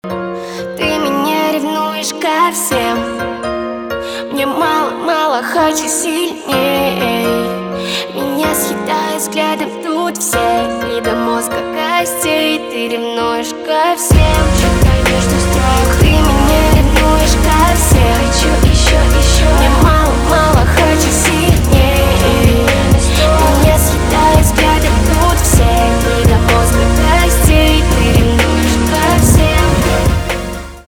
поп
пианино
басы